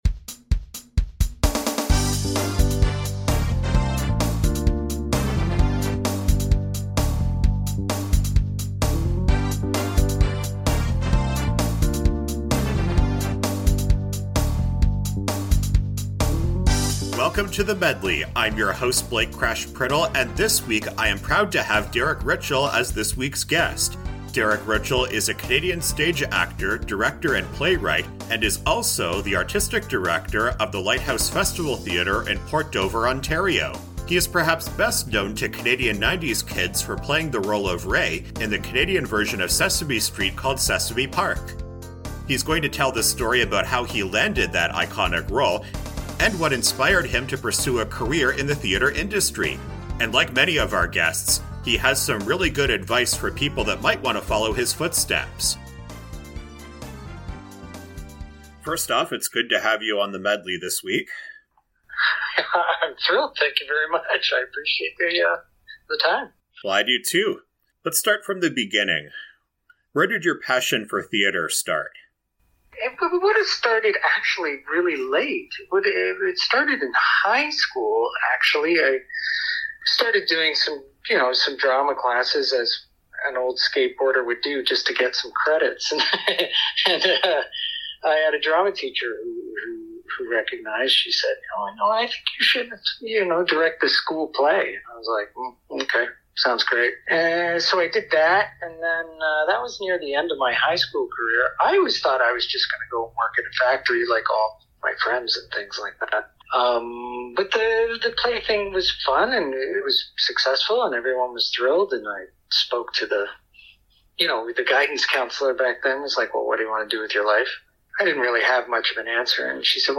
In this interview we shared our own theatre experiences with ...